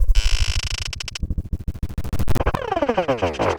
Glitch FX 06.wav